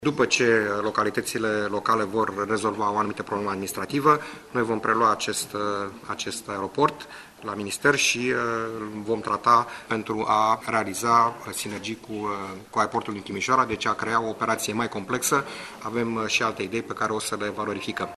Sorin Buşe a mai anunţat că Aeroportul Arad, modernizat recent dar care nu mai are curse regulate, va fi preluat de minister, iar apoi va fuziona cu Aeroportul din Timişoara.